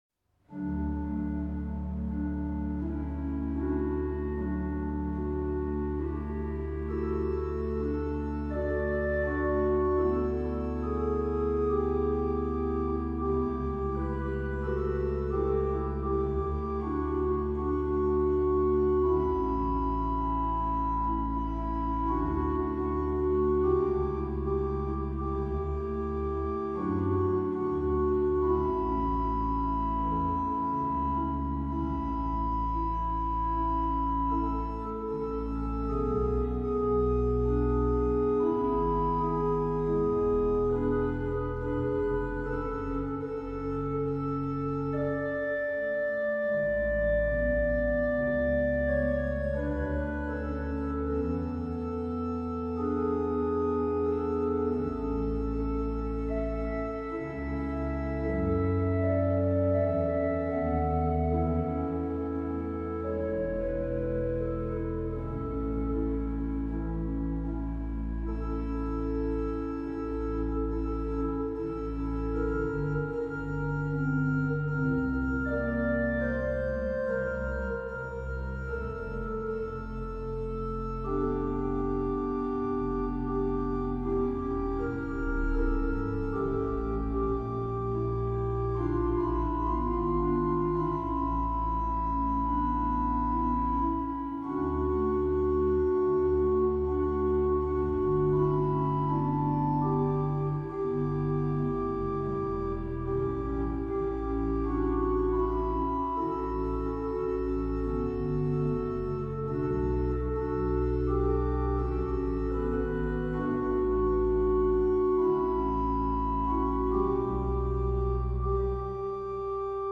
Die Orgel der Heilig-Geist-Kirche